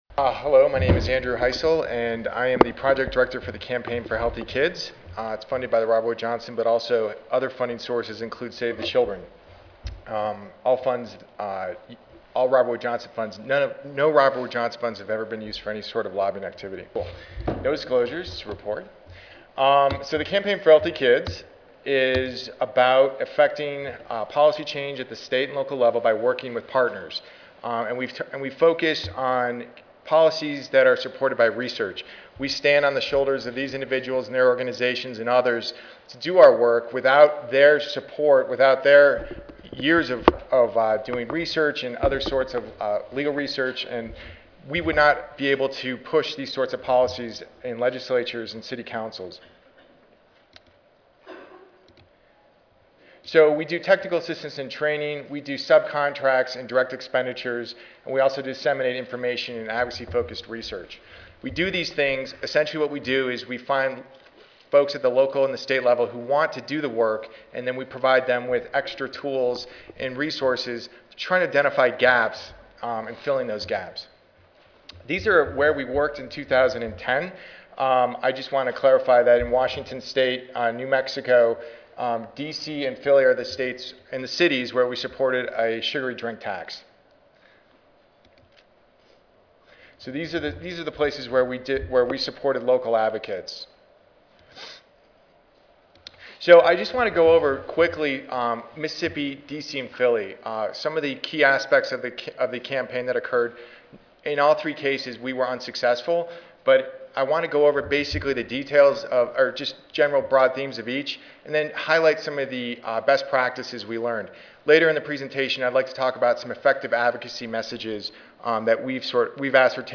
The presenter will also discuss elected officials' attitudes towards SSB taxes and effective messages to win legislative supporters. Finally, the presenter will preview plans for the 2010-2011 state legislative session.